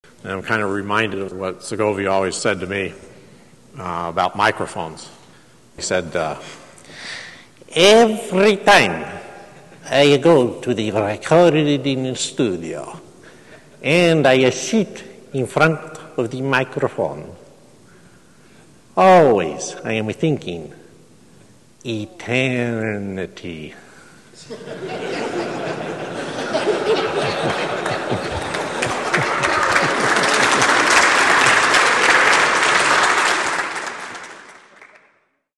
speaking at the 2001 GAL Convention.